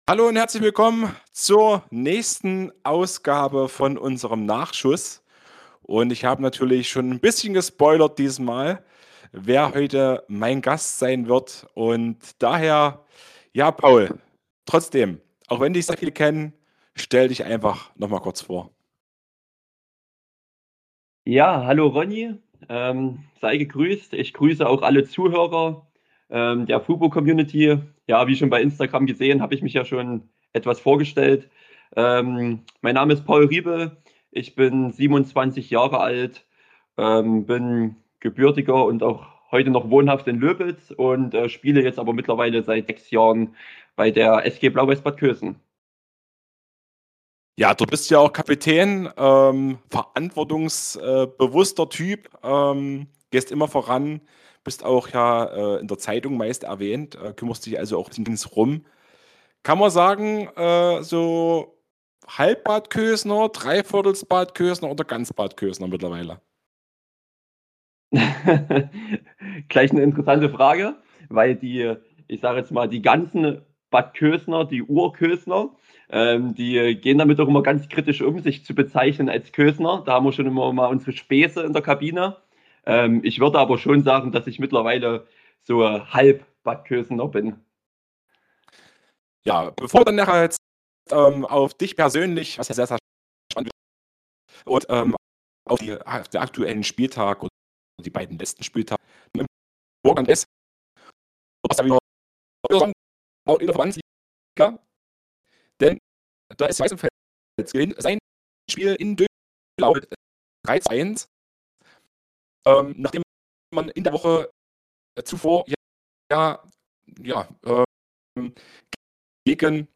Entschuldigt bitte die anfänglichen Tonprobleme auf meiner Tonspur.